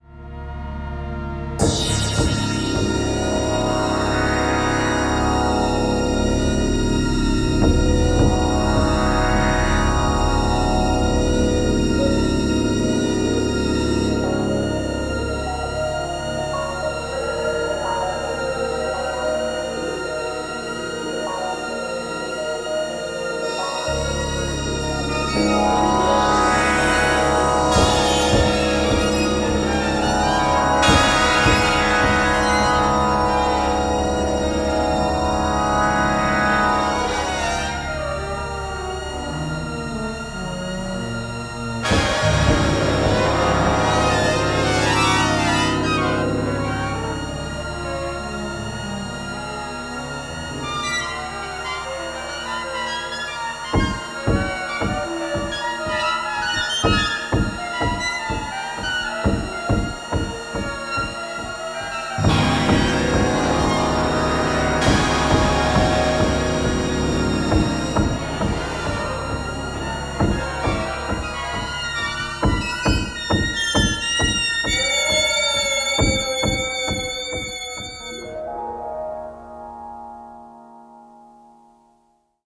a mix of synth-based suspense tracks and catchy pop rhythms
low growling drones of a Moog synthesizer